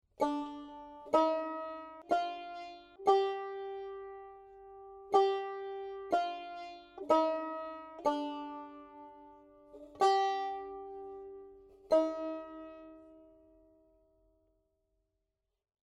– Each of the Dângs and Pângs are played on Târ.